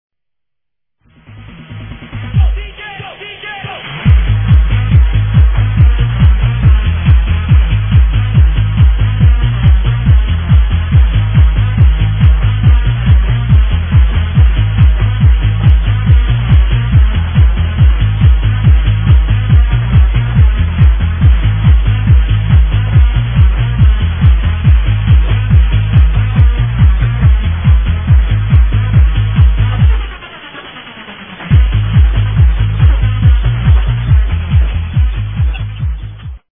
ID: techno track
I just call it trance